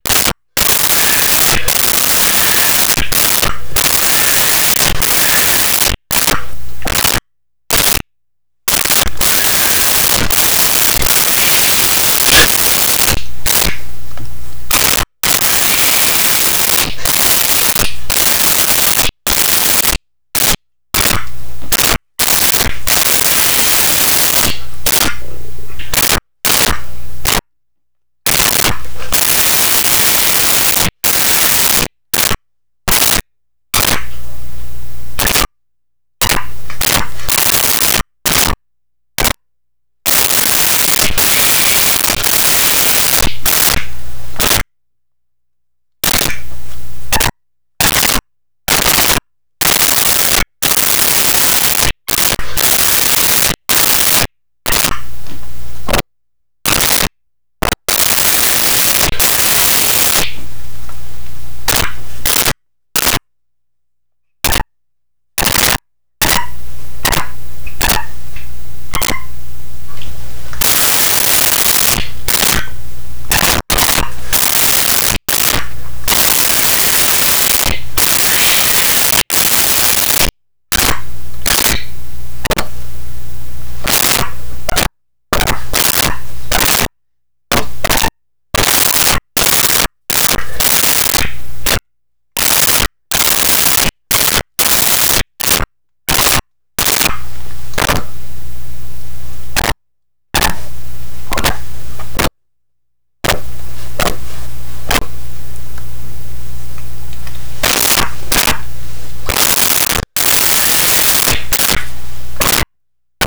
Monkey Angry Chatter
Monkey Angry Chatter.wav